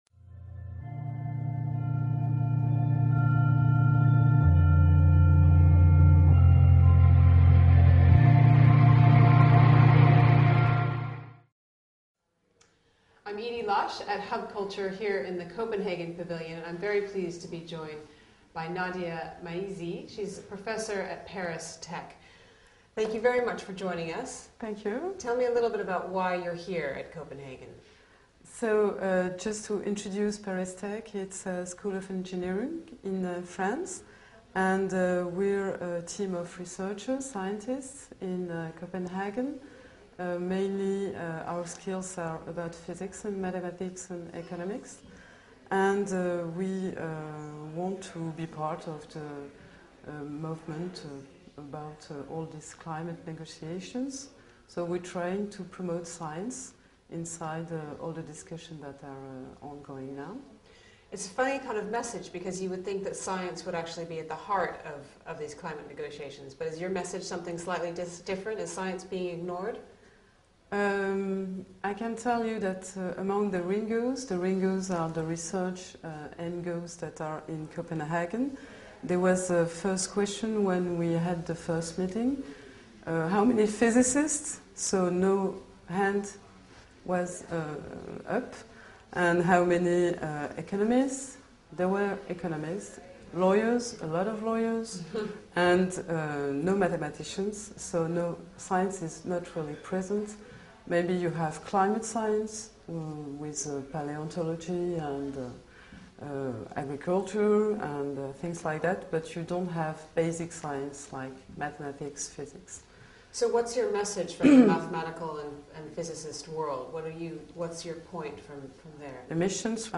Hub_Culture_Pavilion_COP15_Center-for-Applied-Mathematics.mp3